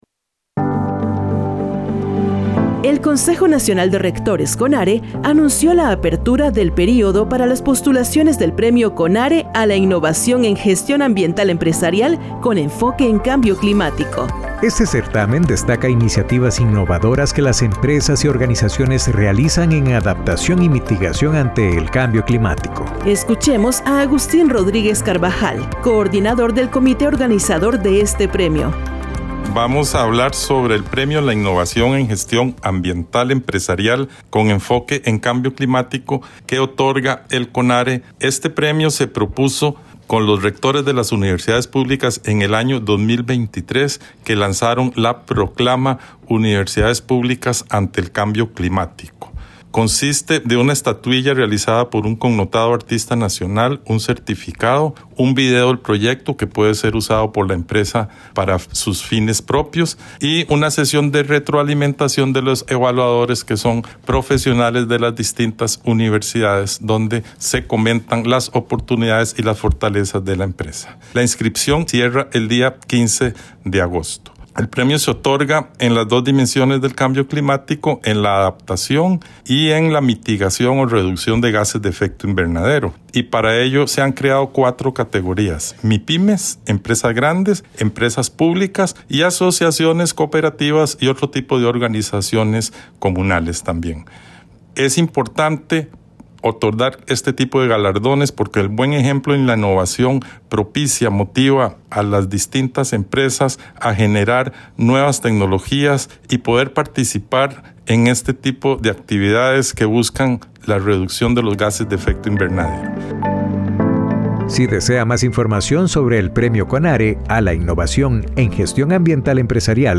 Cápsulas